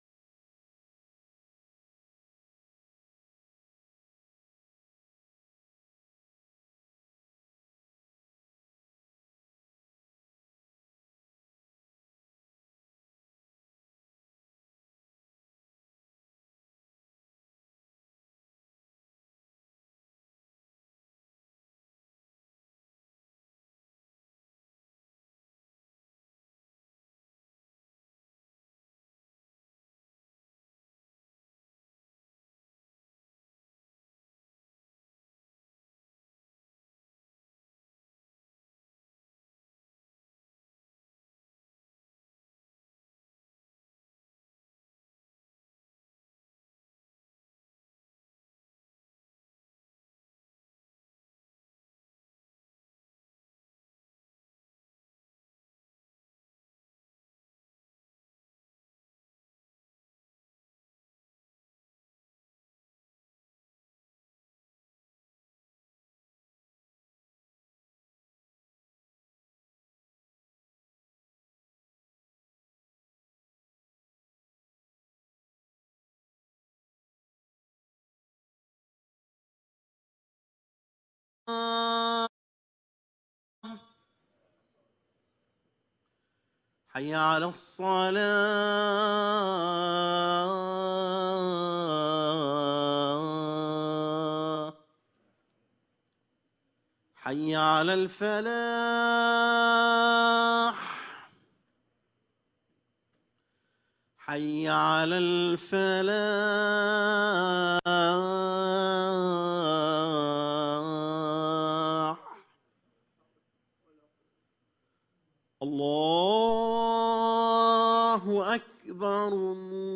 خطبة الجمعة - حول الفاجعة في مدينة هيوستن الأمريكية